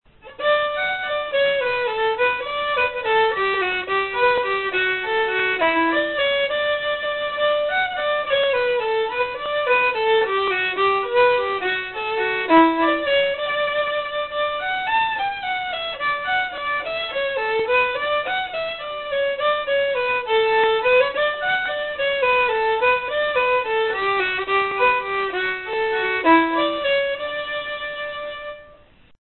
Alawon traddodiadol Cymreig - Set Jig y Ffidlwr - Welsh folk tunes to play